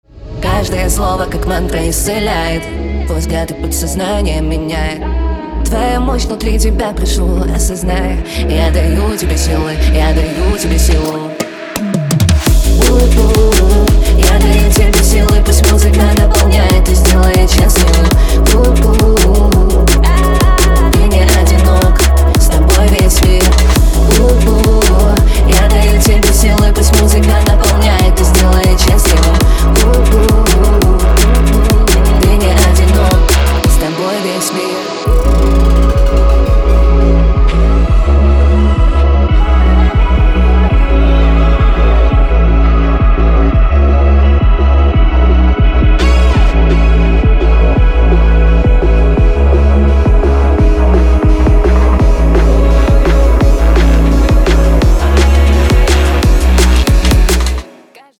Drum & Bass